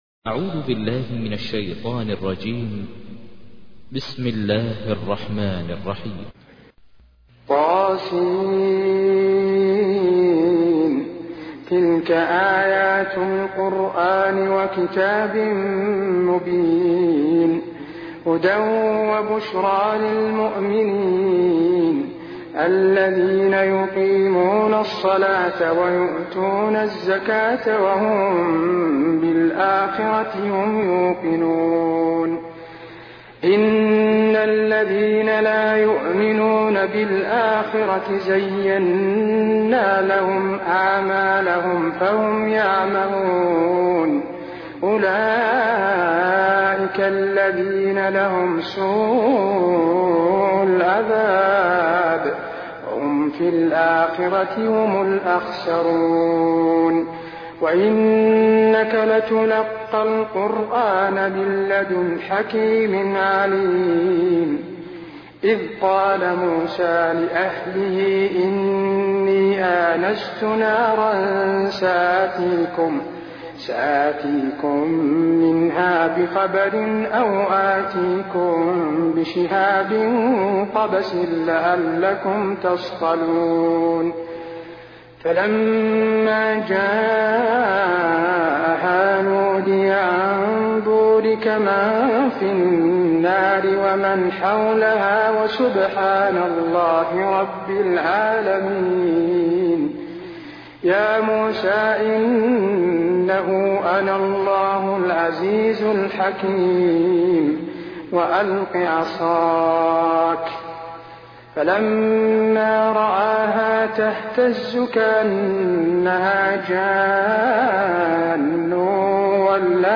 تحميل : 27. سورة النمل / القارئ ماهر المعيقلي / القرآن الكريم / موقع يا حسين